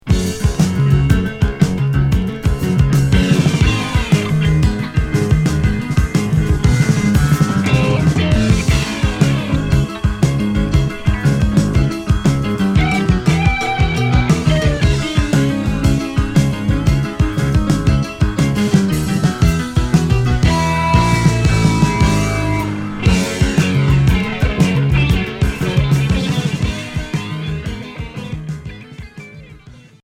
Hard progressif